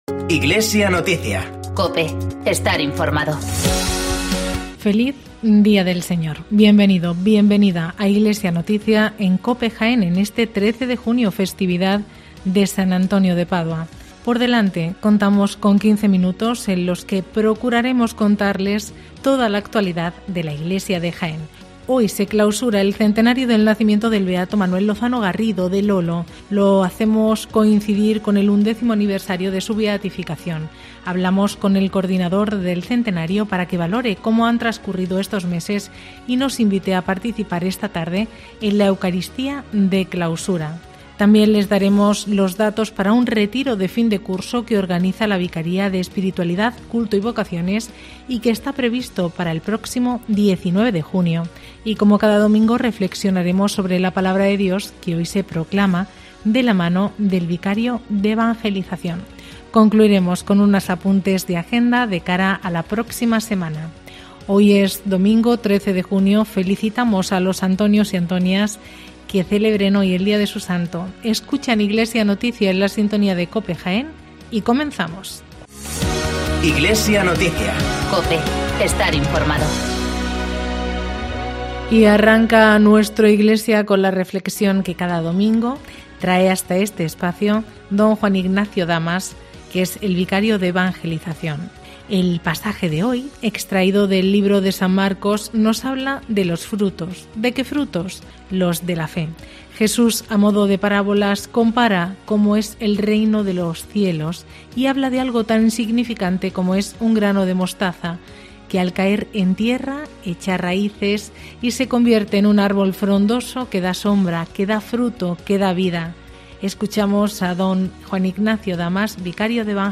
Informativo Iglesia Noticia
AUDIO: En Iglesia Noticia de este domingo escuchamos los testimonios de los periodistas y escritores que se reunieron en torno a la figura del Beato...